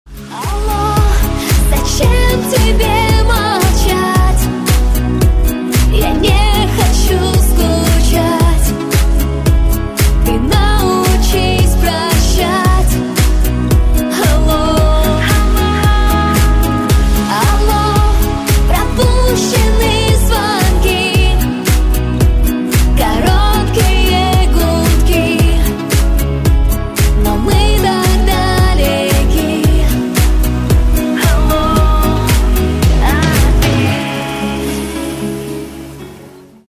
поп
женский вокал
грустные
спокойные